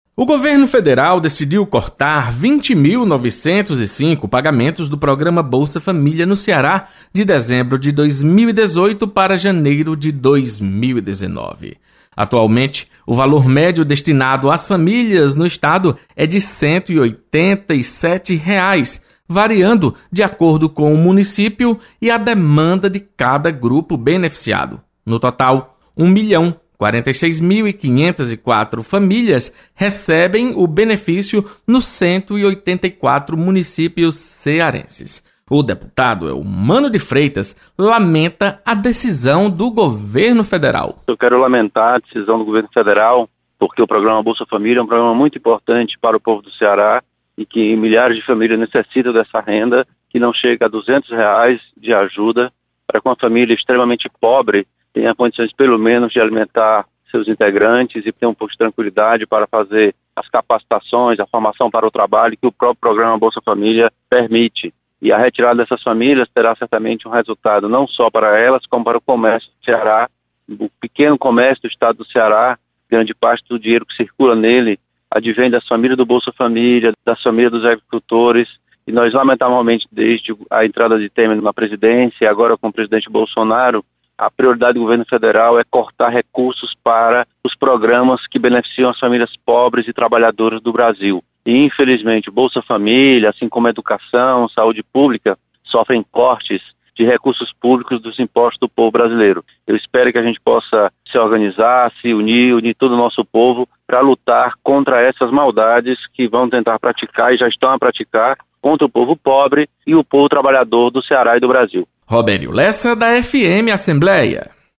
Deputado Elmano de Freitas critica cortes no benefício Bolsa Família no Ceará. Repórter